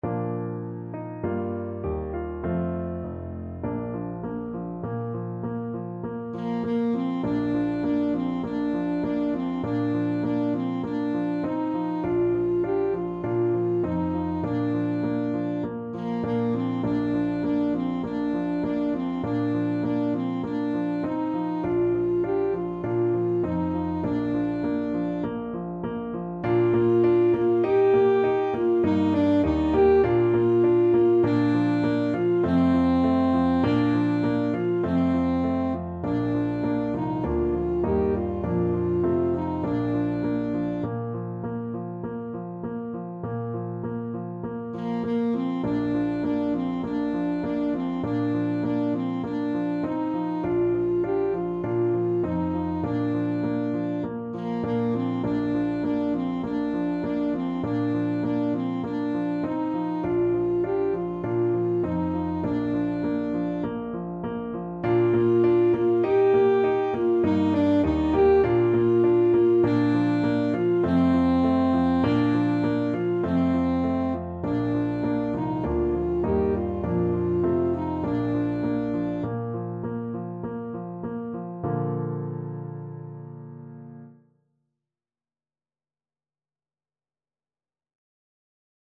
Christmas Christmas Alto Saxophone Sheet Music Es ist fur uns eine Zeit angekommen
Alto Saxophone
4/4 (View more 4/4 Music)
Bb major (Sounding Pitch) G major (Alto Saxophone in Eb) (View more Bb major Music for Saxophone )
Moderato